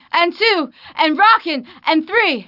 白人慢跑女青年
性别 GTAVCGender Female Icon.png女性
年龄 GTAVCAge Young Icon.png年轻